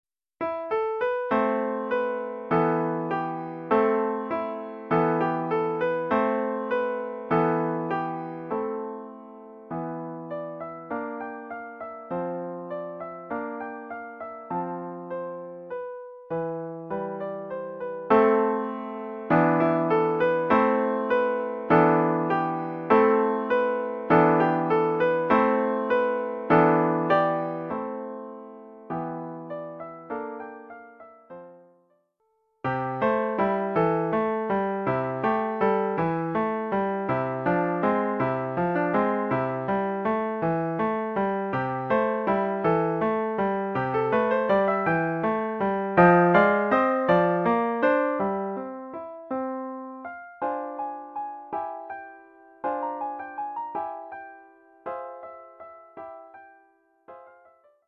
Collection : Piano
Oeuvre pour piano solo.